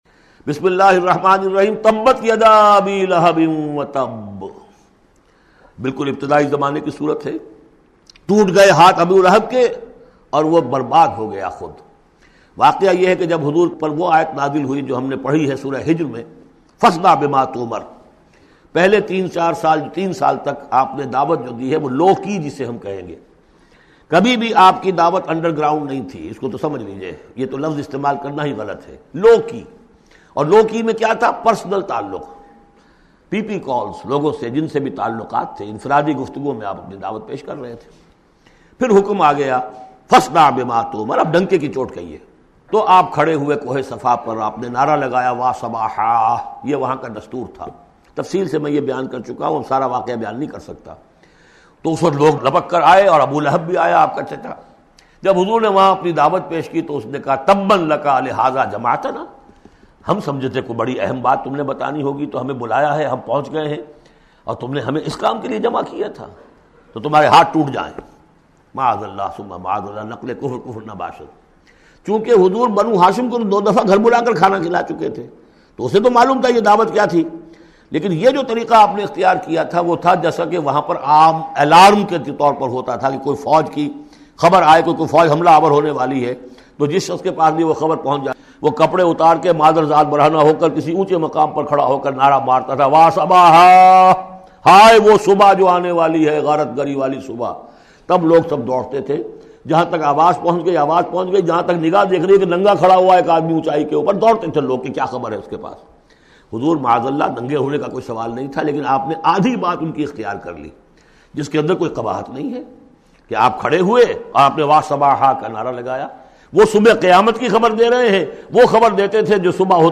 Surah Lahab or Surah Masad is 111 chapter of Holy Quran. Listen online mp3 audio tafseer of Surah Lahab in the voice of Dr Israr Ahmed. Download urdu tafseer free in best audio quality.